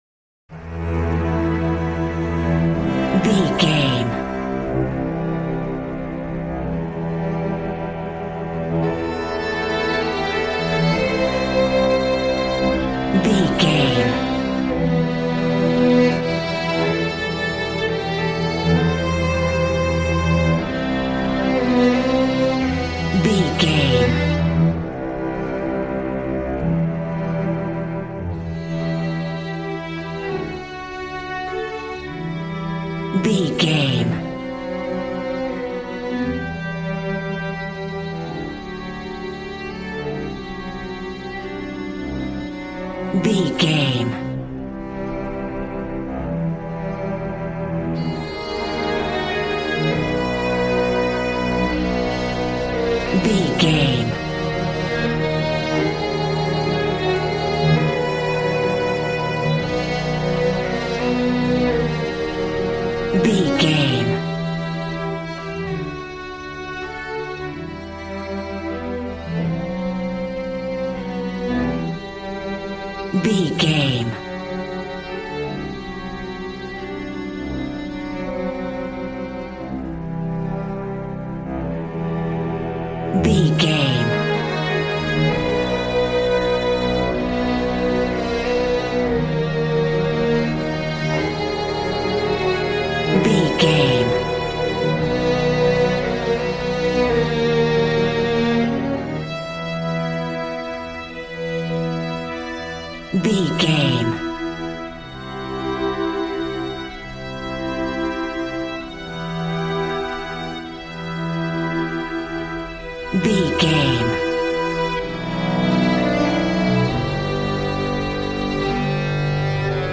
Aeolian/Minor
regal
brass